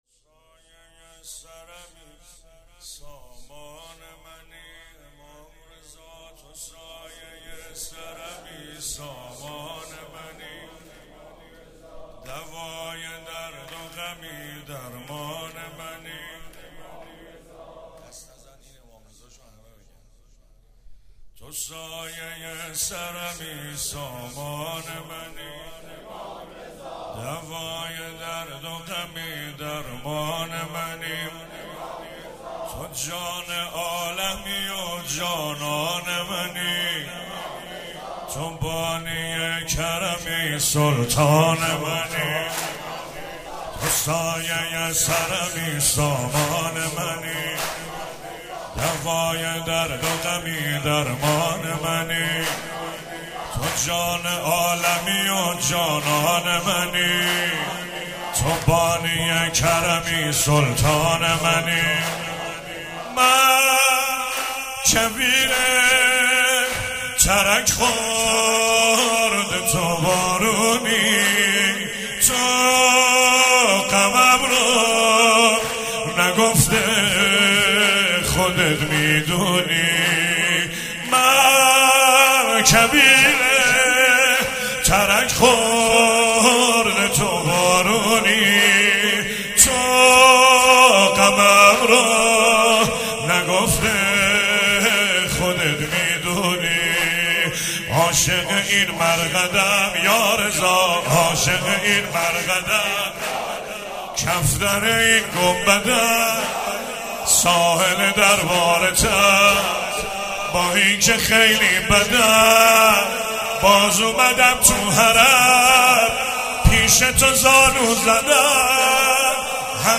چهاراه شهید شیرودی حسینیه حضرت زینب (سلام الله علیها)
سرود